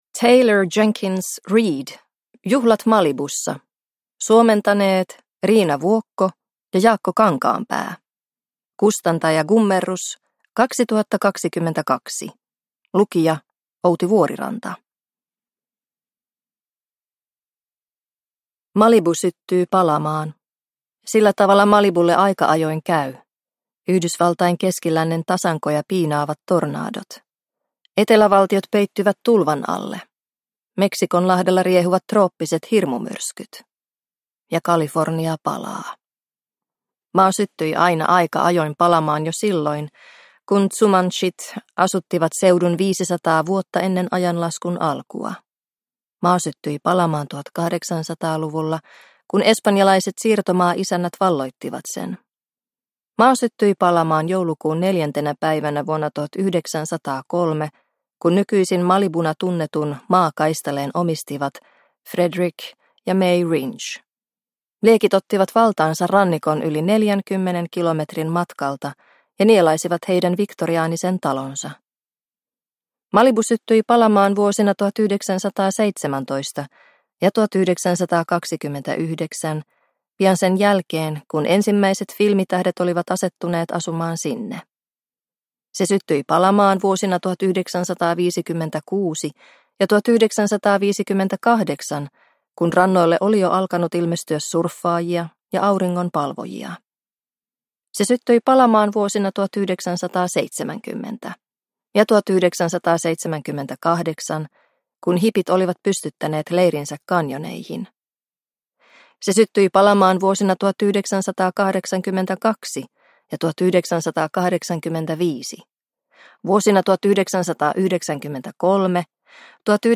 Juhlat Malibussa – Ljudbok – Laddas ner